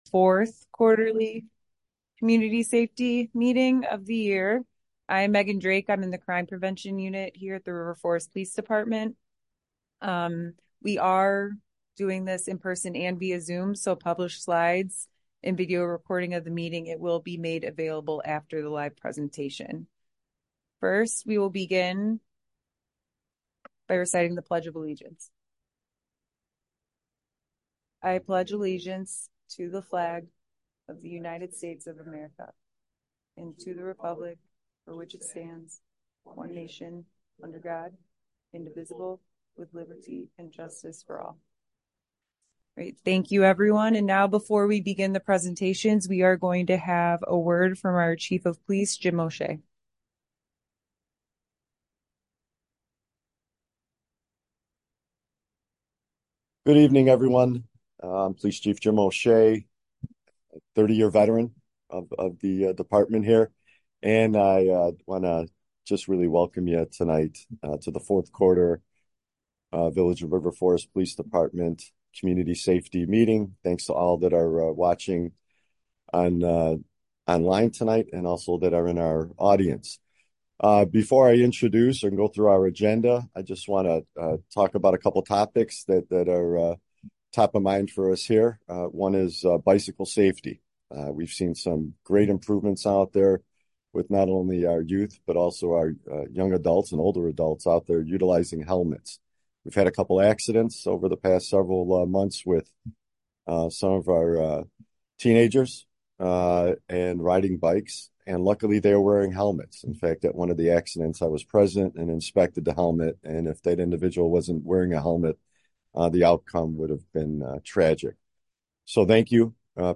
Village Hall - 1st Floor - COMMUNITY ROOM - 400 Park Avenue - River Forest
Quarterly Community Safety Meeting October 22, 2024, 6:30–8 pm Village Hall - 1st Floor - COMMUNITY ROOM - 400 Park Avenue - River Forest Add to calendar The purpose of the meeting is to update residents and encourage their involvement in the community while assisting the River Forest Police Department prevent, stop, and solve crime. We will have presentations from special guest community partners as well as members of our own police department. The meeting will conclude with an open forum for community member questions, comments, and concerns.